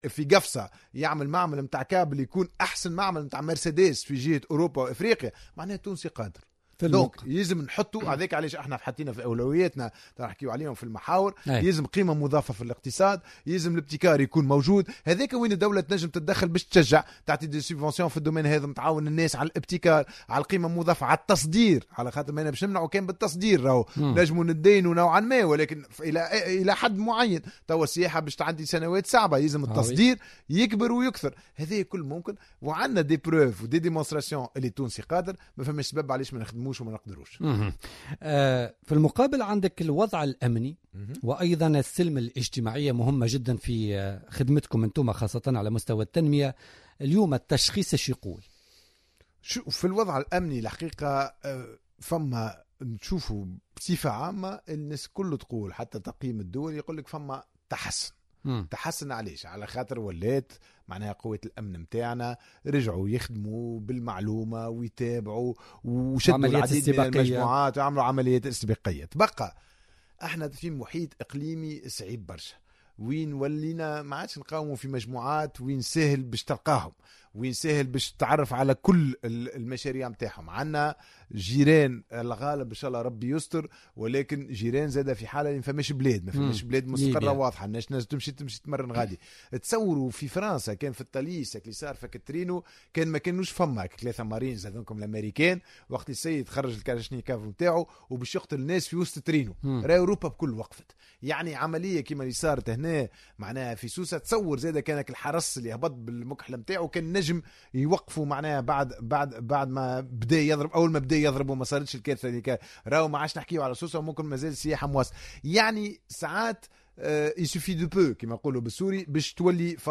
أكد وزير التنمية والاستثمار والتعاون الدولي ياسين ابراهيم ضيف بوليتيكا اليوم الإثنين 14 سبتمبر 2015 أن هناك تحسن ملحوظ على المستوى الأمني يظهر خاصة من خلال المجهودات التي يقوم بها قوات الأمن الدين يعتمدون على المعلومات والتدخلات الإستباقية على حد قوله.